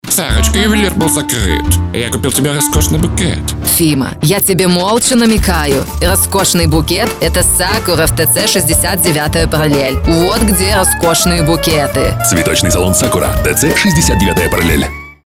Тракт: Конденсаторный микрофон SE ELECTRONICS SE X1 VOCAL PACK, USB-АУДИО ИНТЕРФЕЙС ROLAND QUAD-CAPTURE